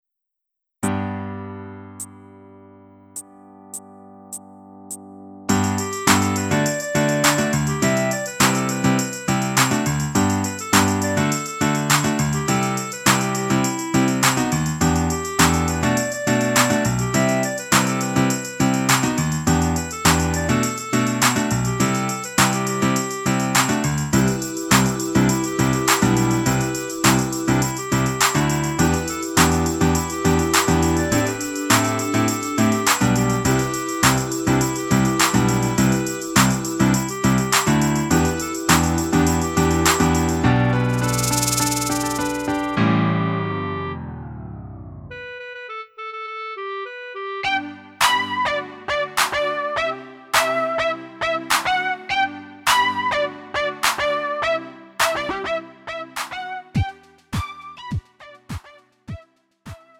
음정 원키 3:14
장르 구분 Lite MR